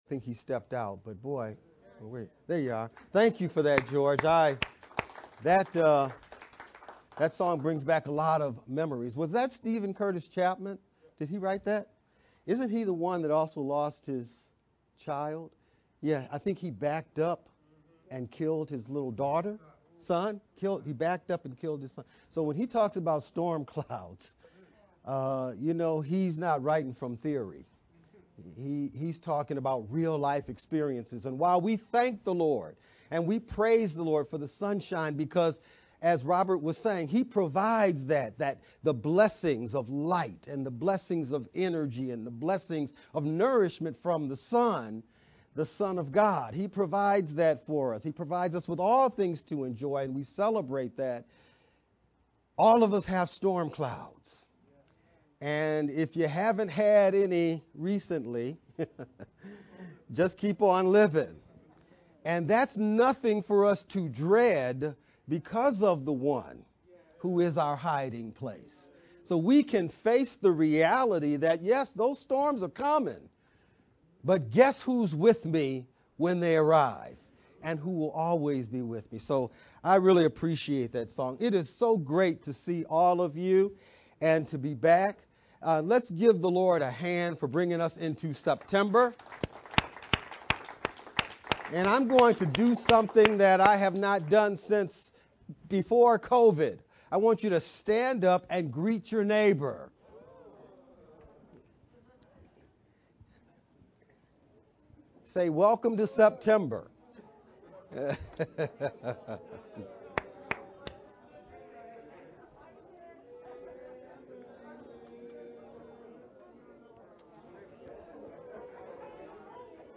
VBCC-Sermon-edited-9-4-sermon-only-Mp3-CD.mp3